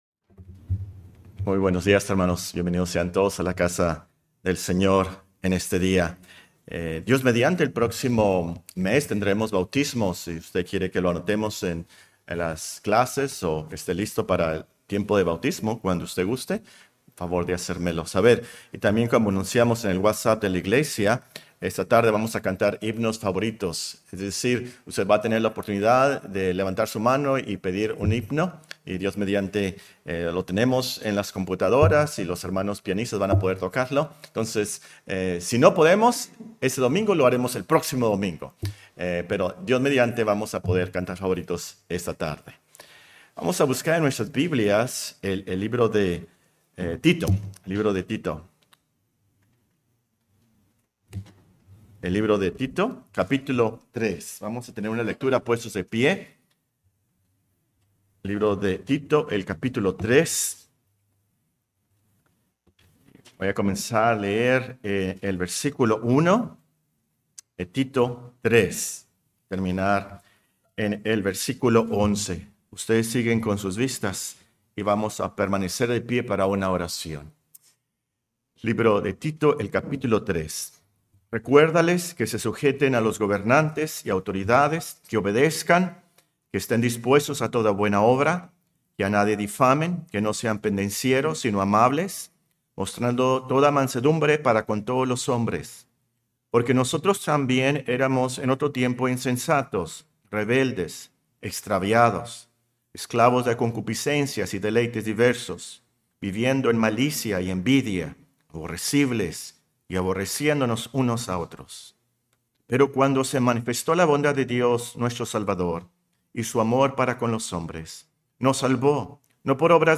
Serie de sermones Matrimonios Sanos